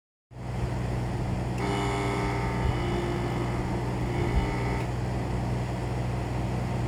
Bruit de pompe à chaleur de piscine anormal
Notre pompe à chaleur de piscine fait un bruit anormal.
Le ventilateur fonctionne bien mais un autre bruit strident et récurrent intervient.
Apparemment, ce n'est pas qu'un bruit, en fait la PAC veut se lancer mais son compresseur s'arrête au bout de 2 bonnes secondes, non?
En fait, la pompe se met en route normalement et produit en plus ce son de façon répétée.
Puis, apparait de façon répétée mais non continue ce bruit de moteur d'avion !
pompe-a-chaleur-acl.mp3